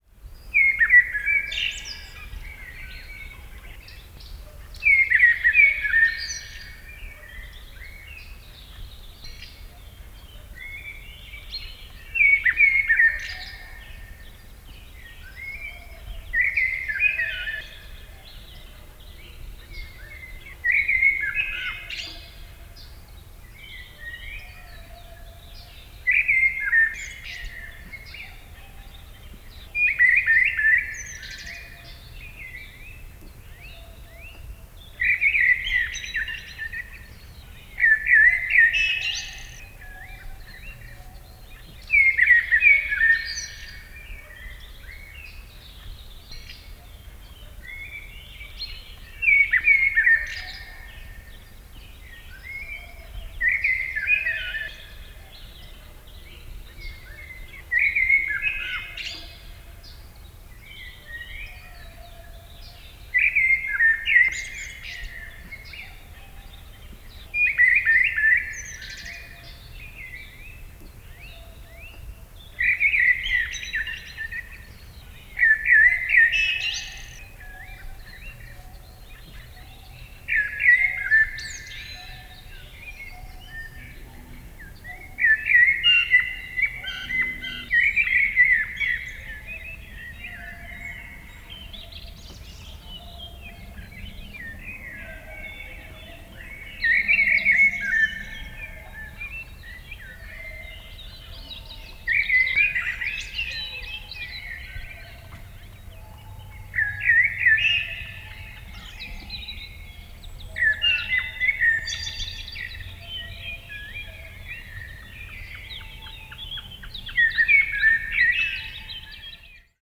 Kuuntele hetki mustarastaan laulua: